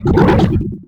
attack1.wav